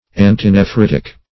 Search Result for " antinephritic" : The Collaborative International Dictionary of English v.0.48: Antinephritic \An`ti*ne*phrit"ic\ ([a^]n"t[i^]*n[-e]*fr[i^]t"[i^]k), a. (Med.) Counteracting, or deemed of use in, diseases of the kidneys.